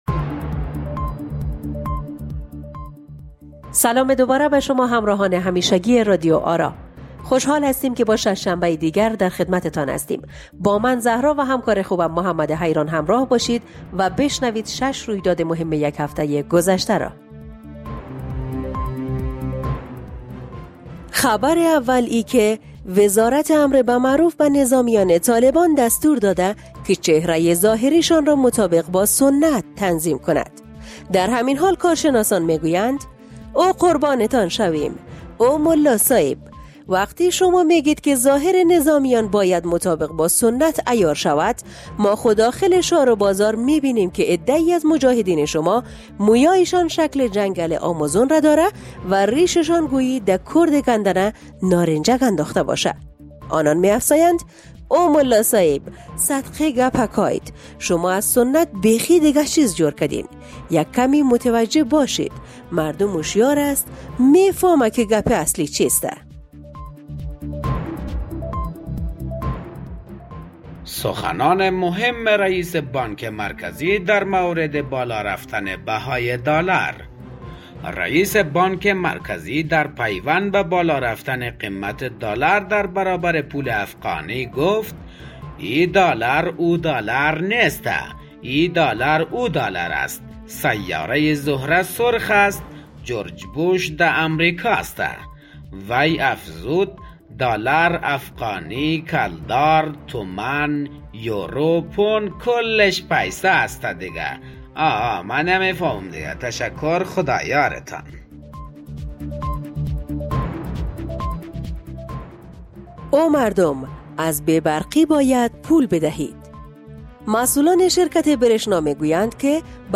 《شش شنبه》شش خبر، شش رویداد، شش خنده، شش گریه، شش حقیقت، شش مشکل، شش بیان و شش هنر است.
گوینده